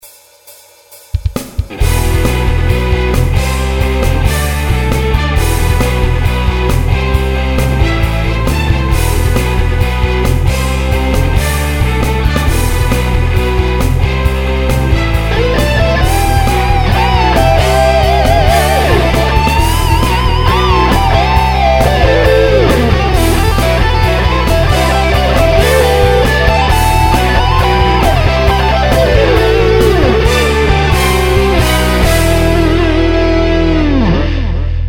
na mna to je cele prehallene a preverbene..
Gitary su ostrejsie v druhej nahravke. Aj na sole je menej delayu.
Sólo je celkom dobré, akurát na ten posledný podladený tón :)
Ekvalizačne sú bicie tiež slabé a nevýrazné...Tie sláky, no čo ja viem?